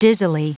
Prononciation du mot dizzily en anglais (fichier audio)
Prononciation du mot : dizzily